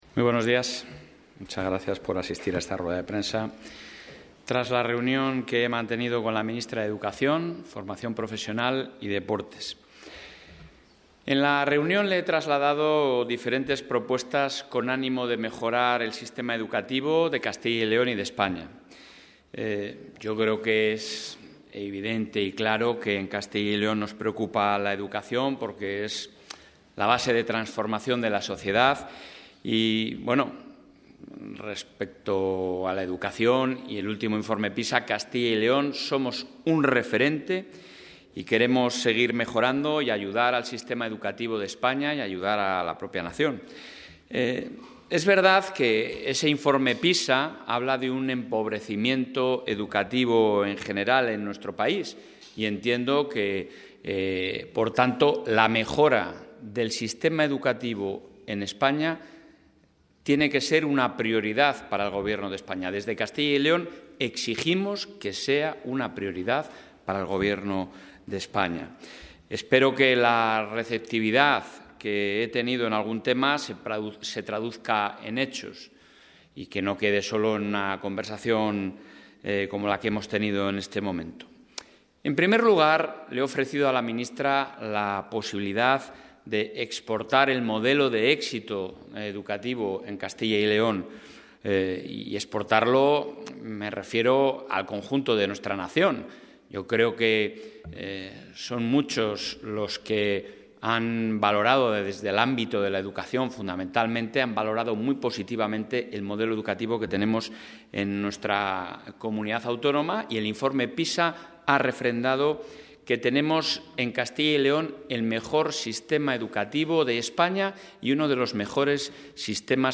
Valoración del presidente de la Junta.
Rueda de prensa tras la reunión con la ministra